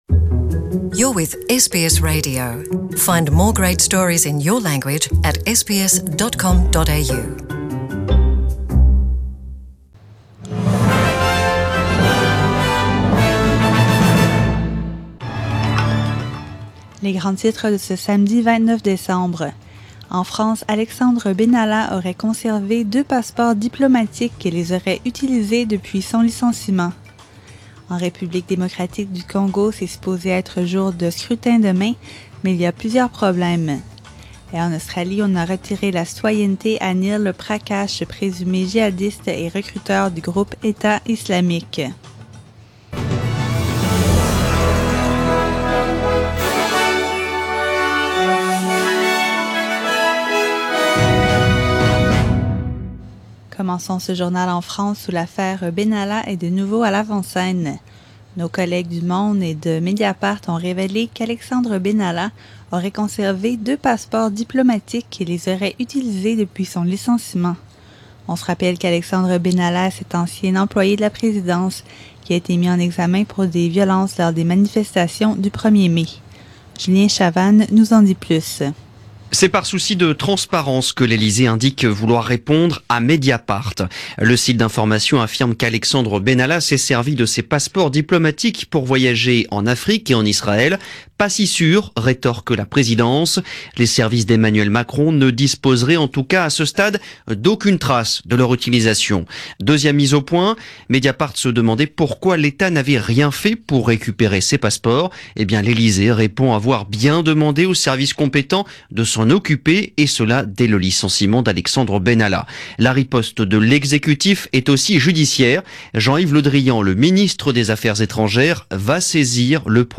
SBS French : Journal du 29 décembre